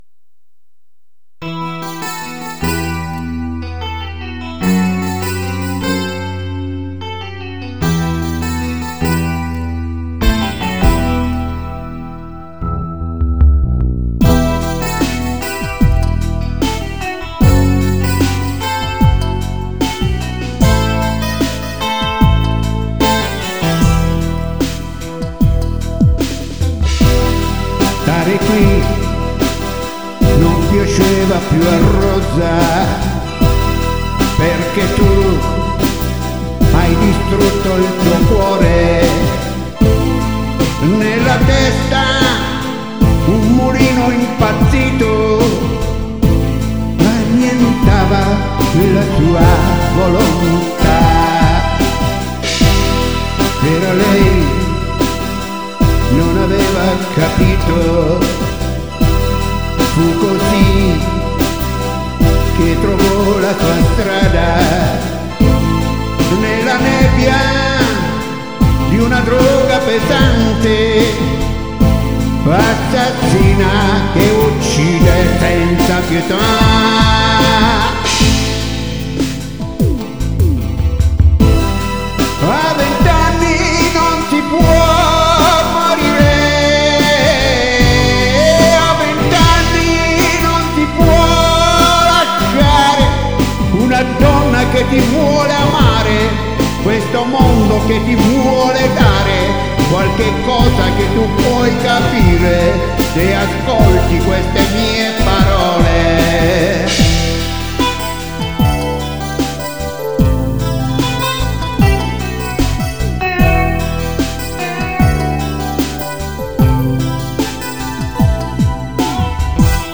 Mentre io (noi sconosciuti), con attrezzatura da ridere, con uno strumento musicale, tiro fuori le mie canzoni, quasi come per magia, registrate in casa con un microfonino da 70 euro.